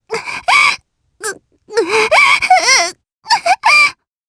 Viska-Vox_Sad_jp.wav